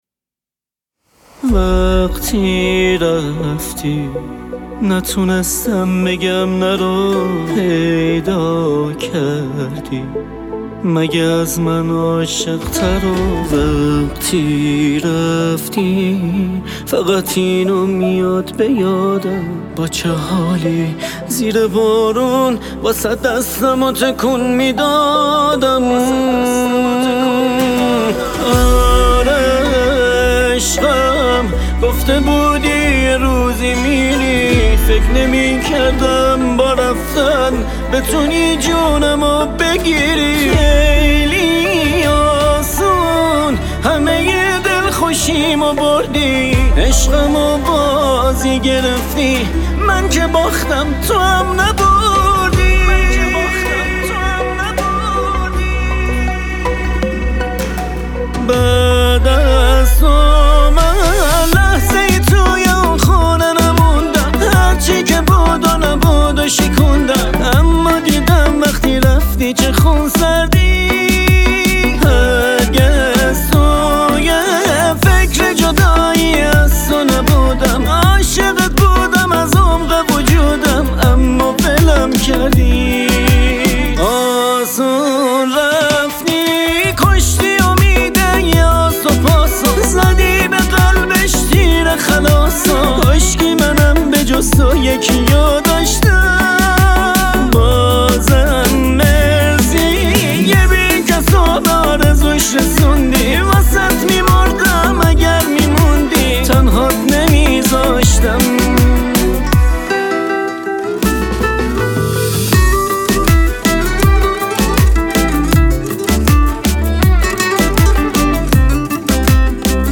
گیتار .